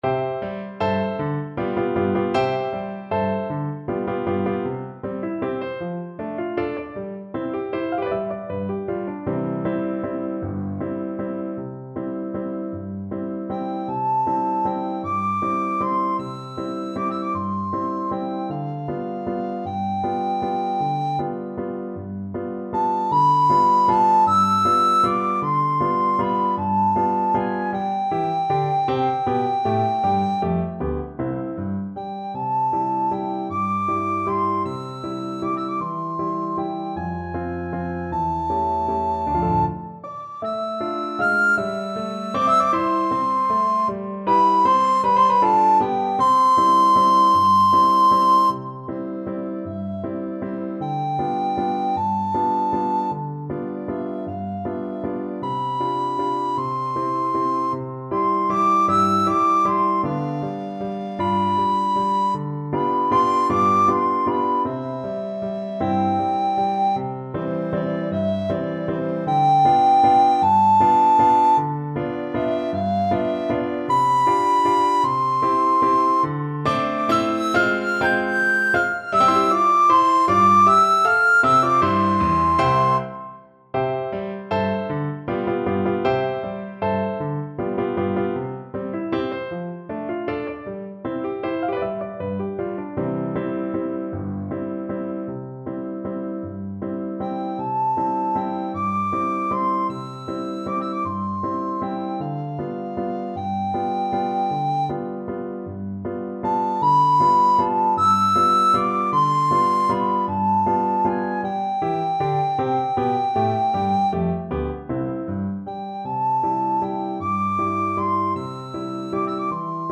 Soprano (Descant) Recorder
6/8 (View more 6/8 Music)
Allegretto . = c.52
Classical (View more Classical Recorder Music)
Neapolitan Songs for Recorder